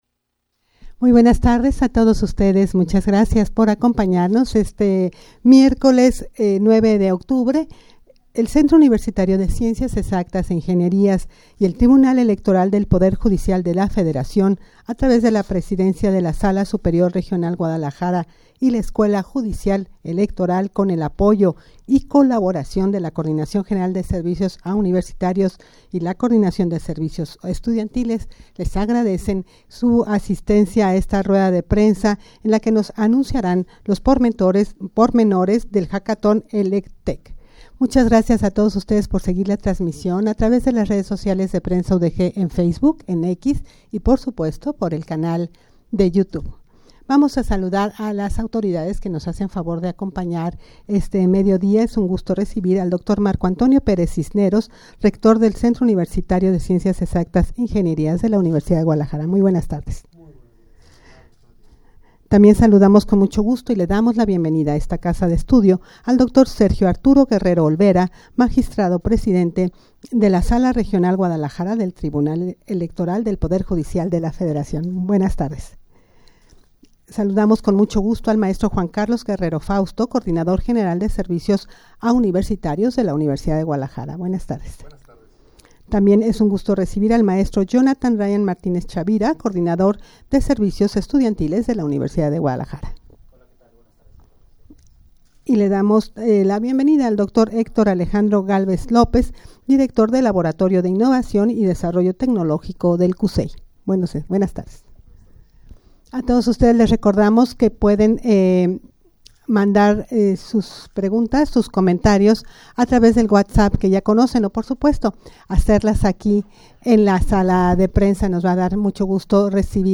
Audio de la Rueda de Prensa
rueda-de-prensa-para-anunciar-el-hackaton-elec-tech.mp3